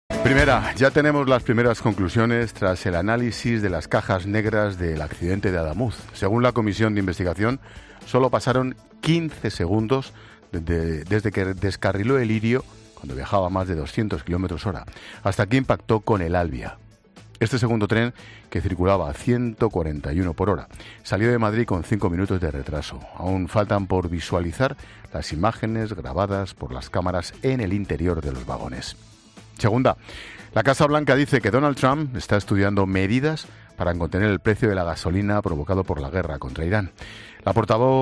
Expósito informa sobre las conclusiones de la investigación del accidente de tren en Adamuz